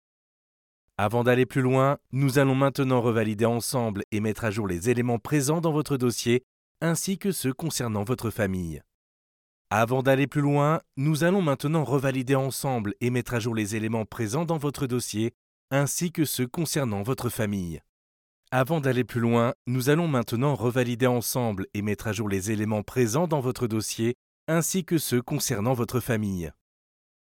Voix off
E-learning Formation